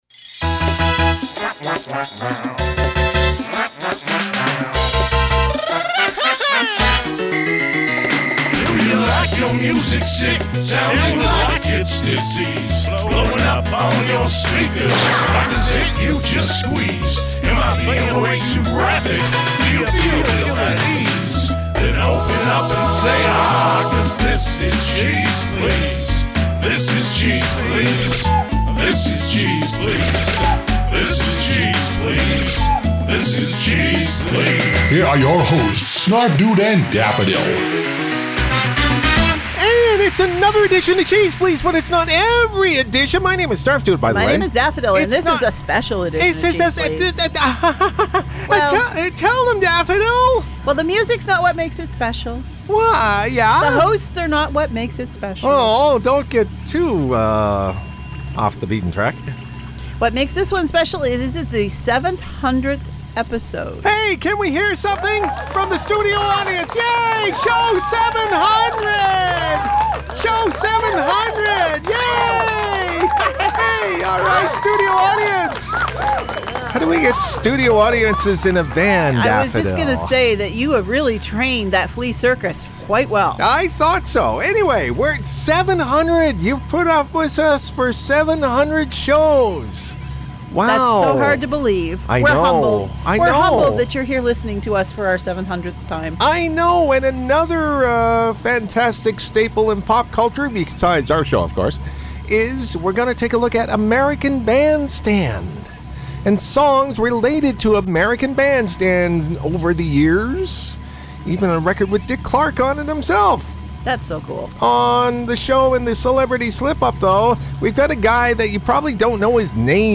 We briefly celebrate show # 700 with out in studio guests.